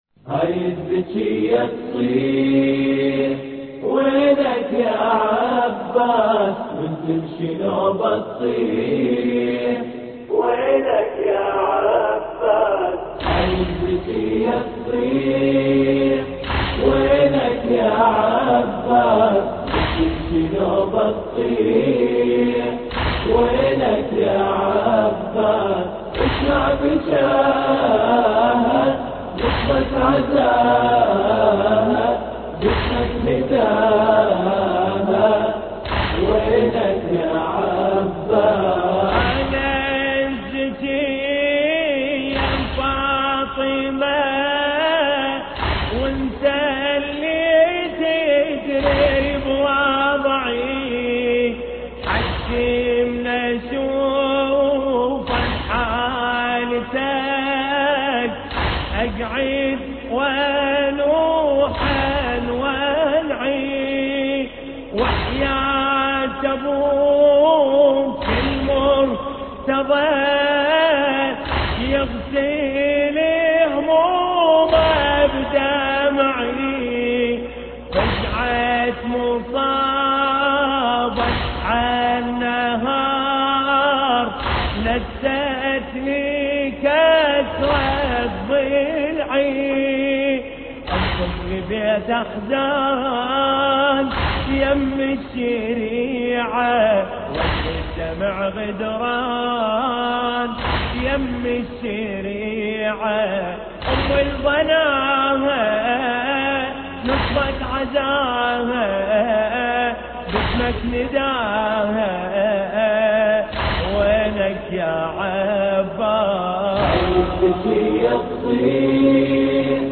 مراثي